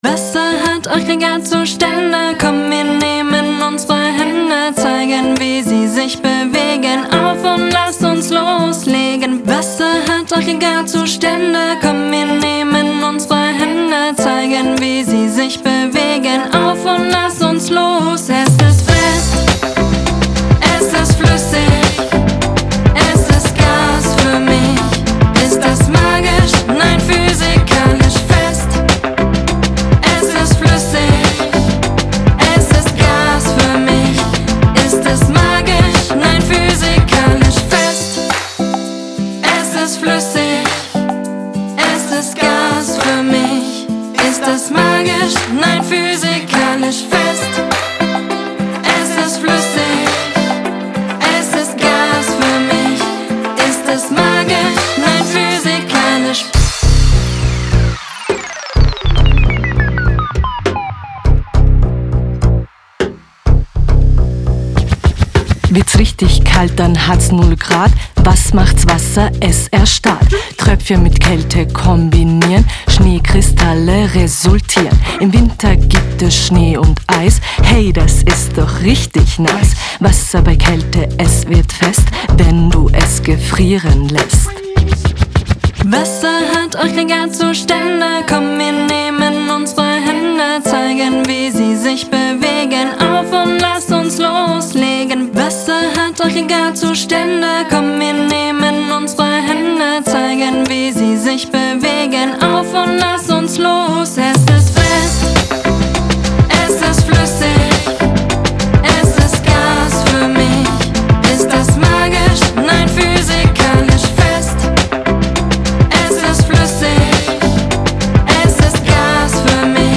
Weihnachtsvorlesung_Aggregatzustände_lang_mit-VOX_mp3.mp3